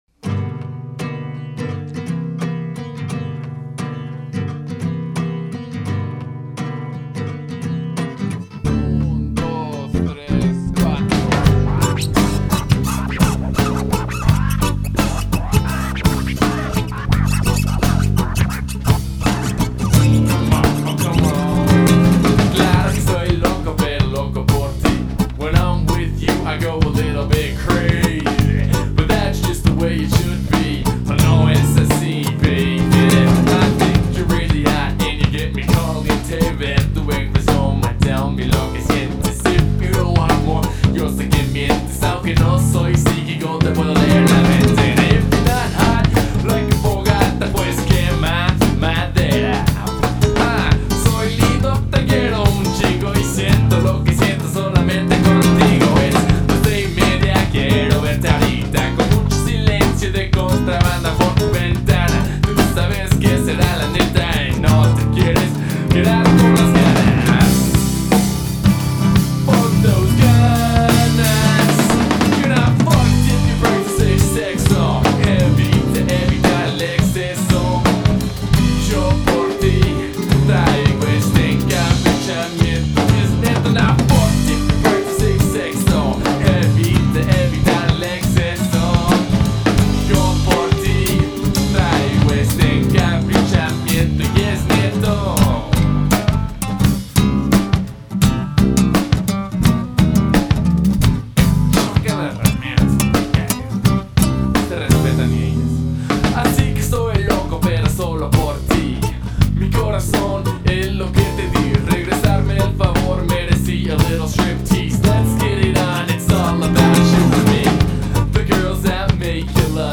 Mezcla de hip hop, rock, rap, breakbeat y funky beats.
Música para gente feliz dispuesta para enormes carcajadas.